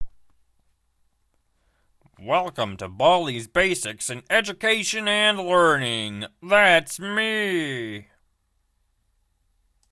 文件 文件历史 文件用途 全域文件用途 BAL_MainMenu.ogg  （Ogg Vorbis声音文件，长度9.9秒，73 kbps） 文件说明 源地址:Baldi菜单界面语音 文件历史 单击某个日期/时间查看对应时刻的文件。